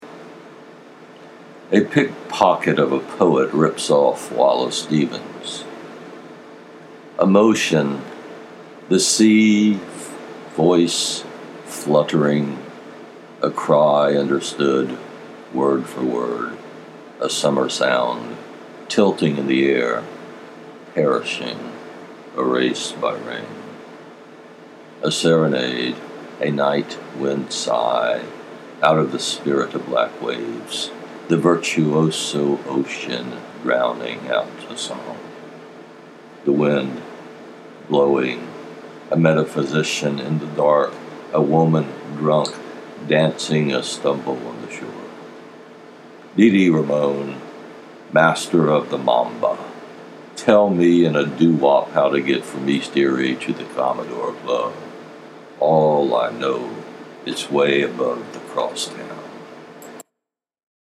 accompanied by a labored window unit